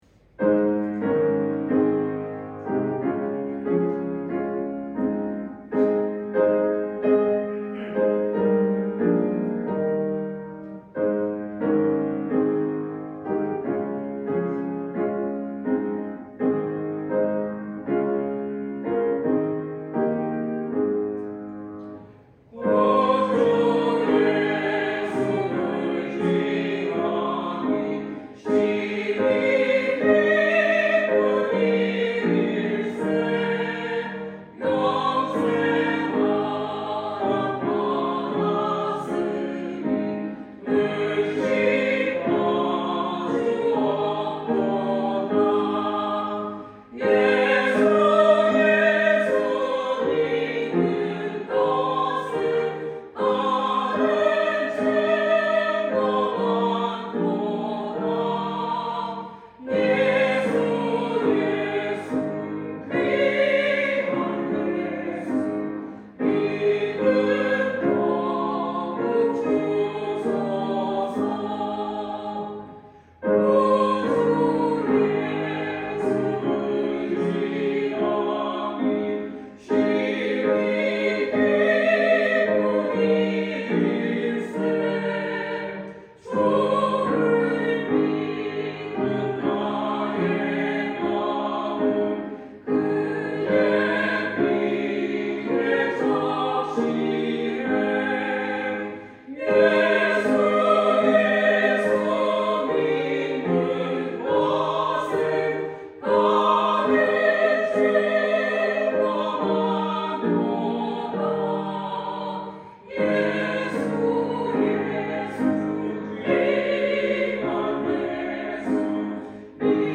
성가대